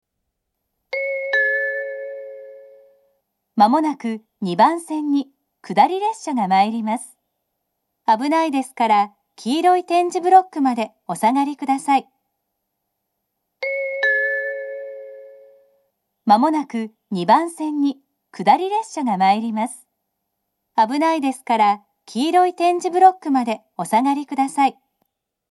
２番線下り接近放送
murayama-2bannsenn-kudari-sekkinn1.mp3